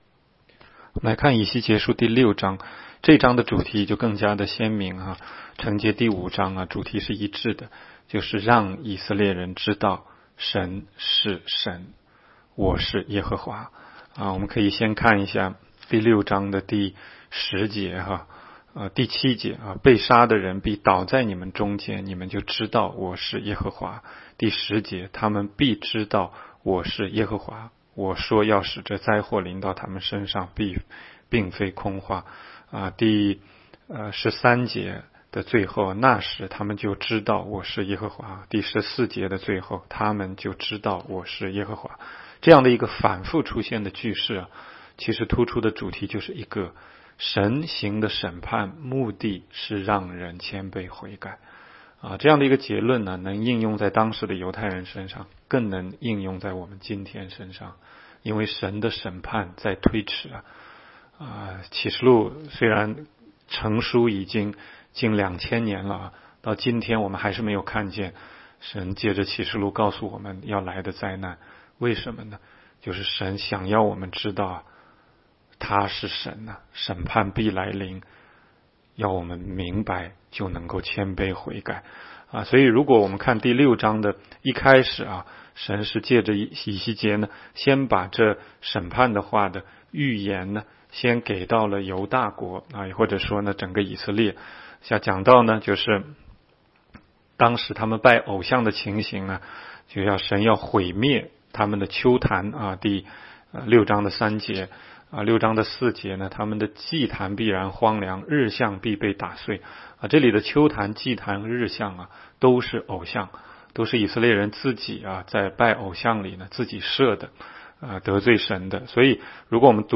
16街讲道录音 - 每日读经 -《以西结书》6章